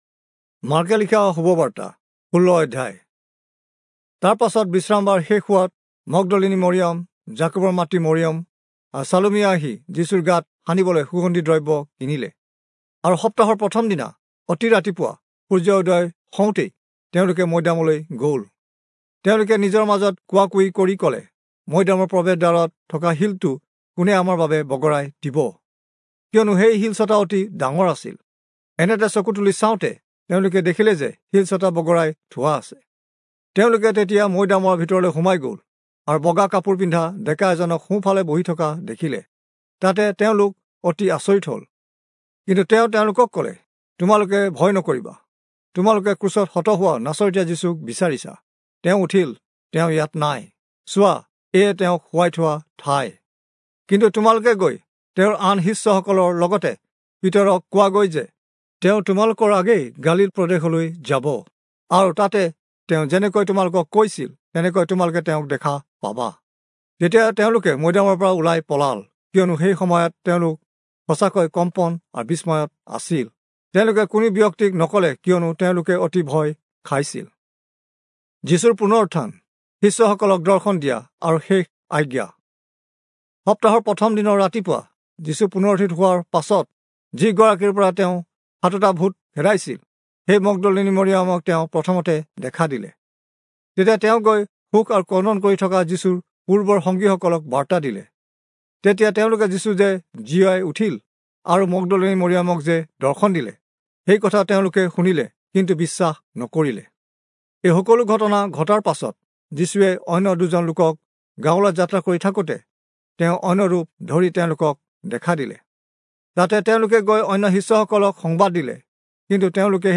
Assamese Audio Bible - Mark 5 in Gntbrp bible version